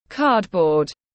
Giấy các tông tiếng anh gọi là cardboard, phiên âm tiếng anh đọc là /ˈkɑːrdbɔːrd/.
Cardboard /ˈkɑːrdbɔːrd/
Cardboard.mp3